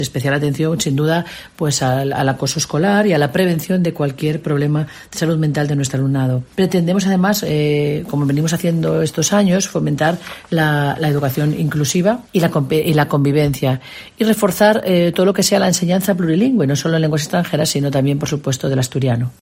Lydia Espina explica los objetivos para el nuevo curso